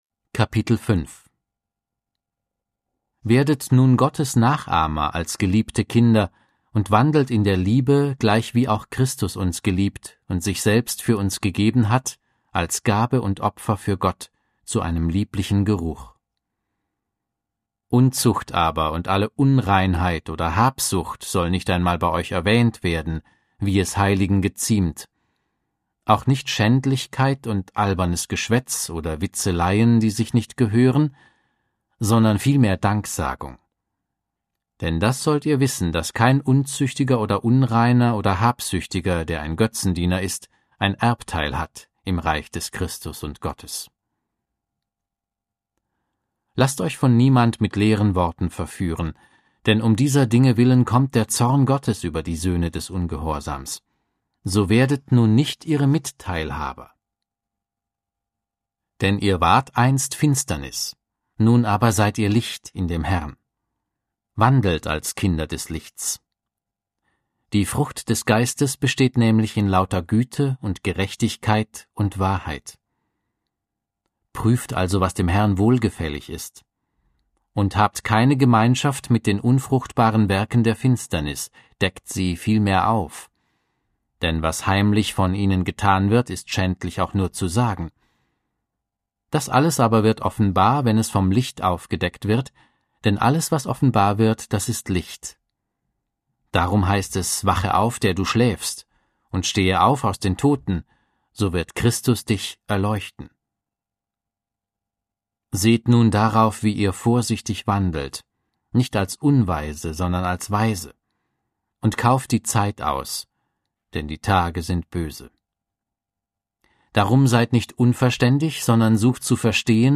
Audio Bibel - Schlachter 2000